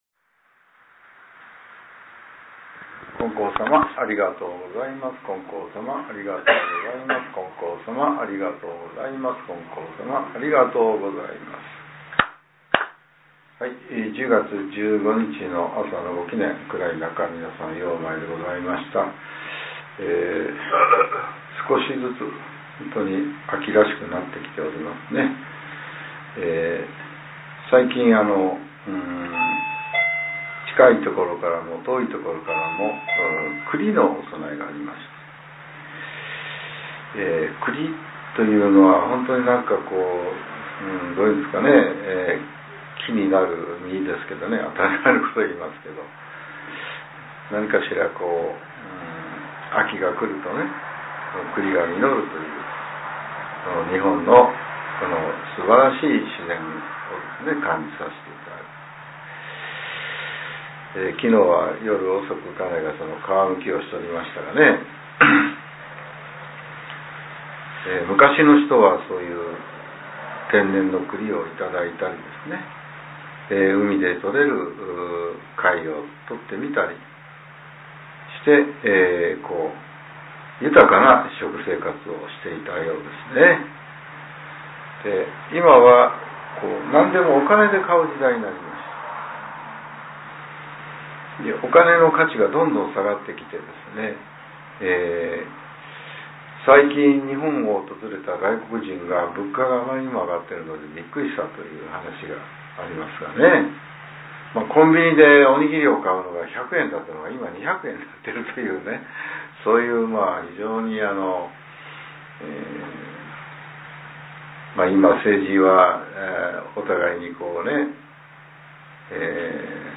令和７年１０月１５日（朝）のお話が、音声ブログとして更新させれています。